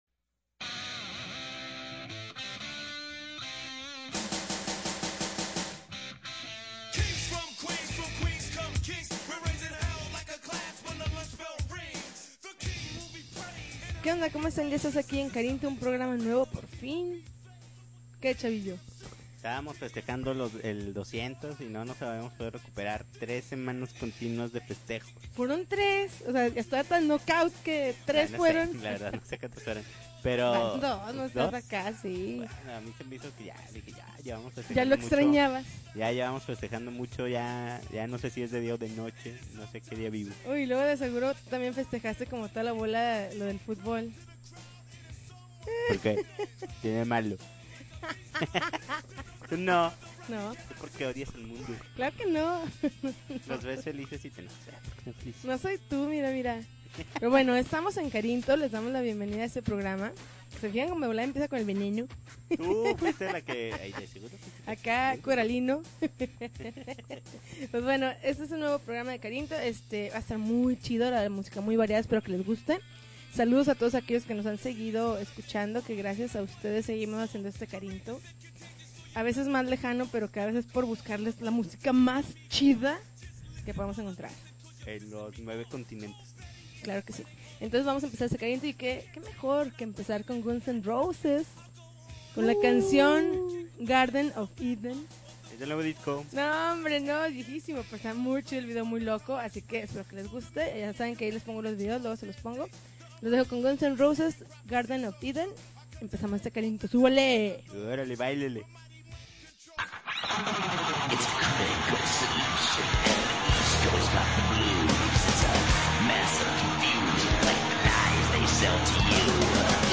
July 12, 2011Podcast, Punk Rock Alternativo